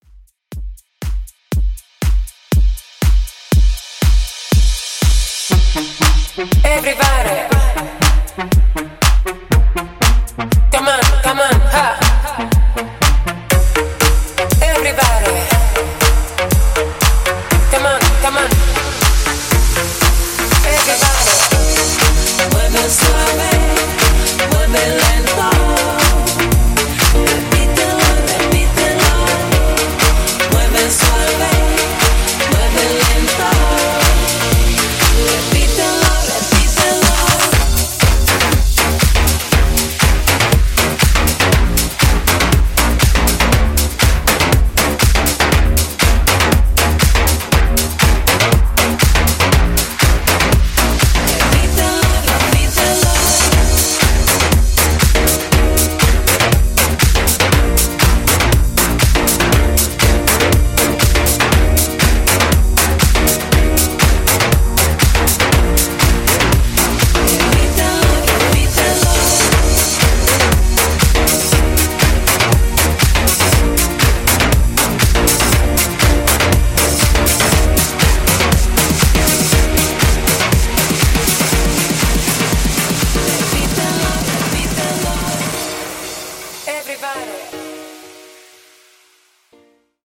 Extended Dance)Date Added